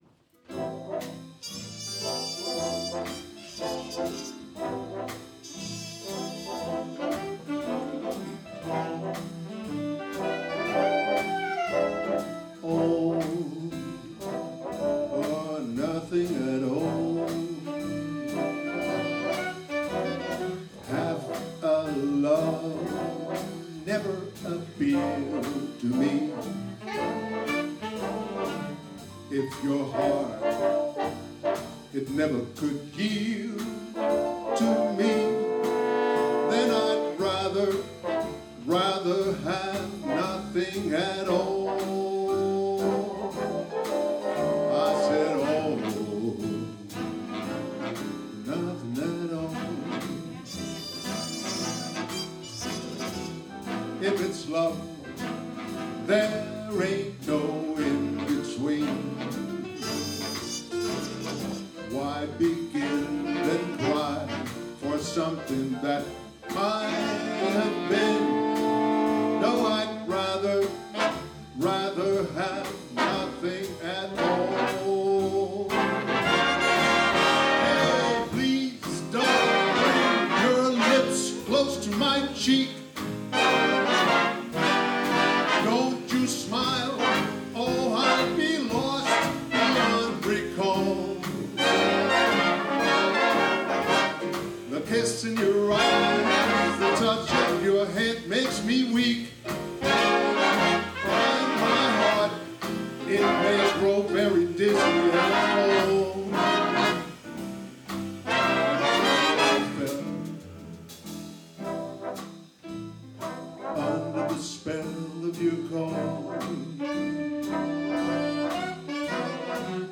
Udstyret er én digital stereo mikrofon, ikke en studieoptagelse !